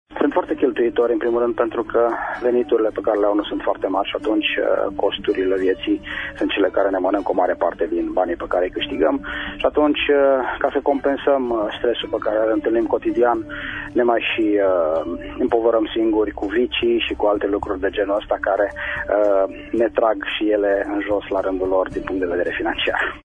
extras emisiunea „Pulsul Zilei”